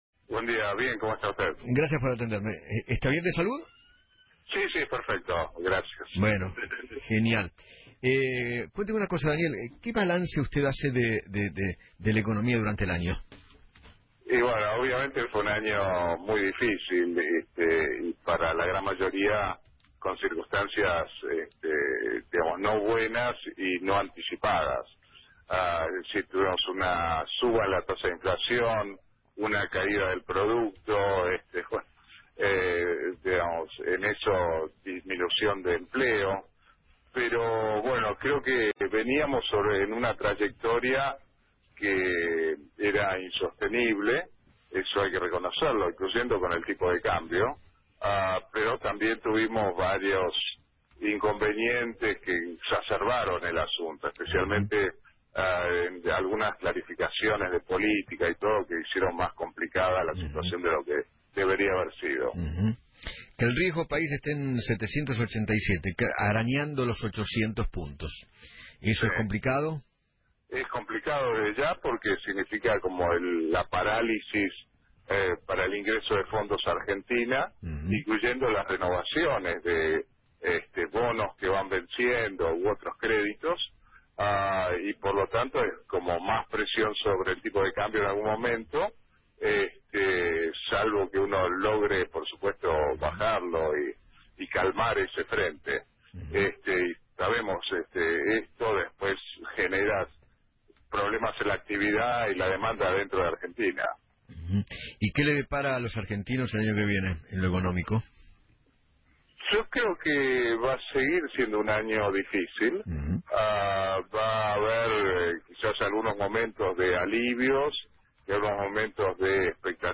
El economista Daniel Marx habló en Feinmann 910 y dijo que “Fue un año dificil para la gran mayoría con circunstancias no buenas y no anticipadas.